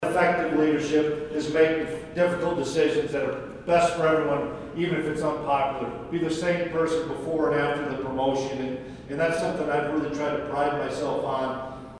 Leadership ideas were shared Wednesday in the annual 60 in 60 event, hosted by the Manhattan Mercury at the Manhattan Conference Center.
The featured guest speaker was K-State head football coach Chris Klieman, who shared his thoughts on what it takes to be a leader.